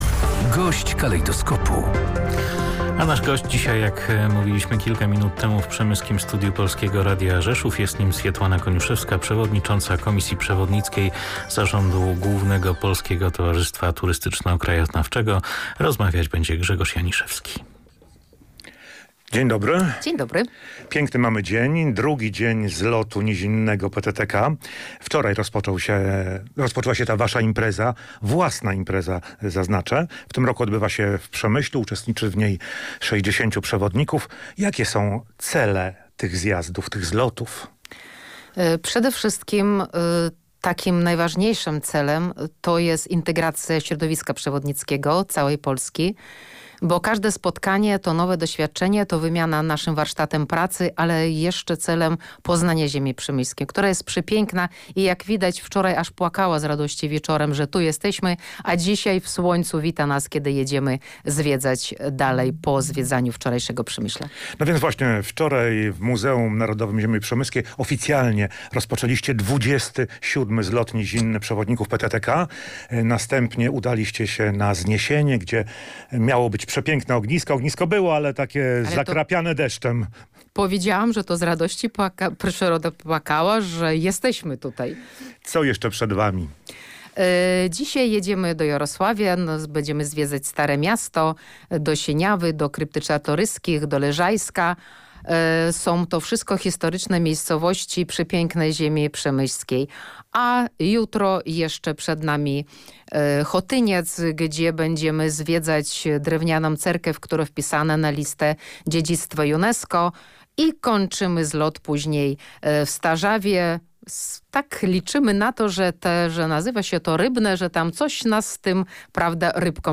Gość dnia • W Przemyślu trwa XXVII Ogólnopolski Zlot Nizinny Przewodników PTTK "Ziemia Przemyska 2025".